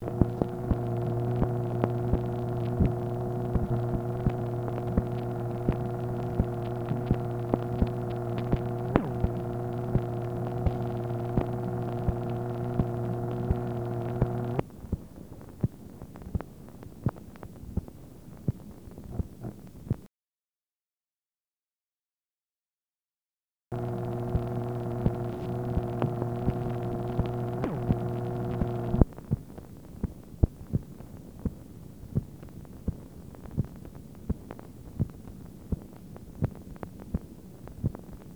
MACHINE NOISE, September 1, 1964
Secret White House Tapes | Lyndon B. Johnson Presidency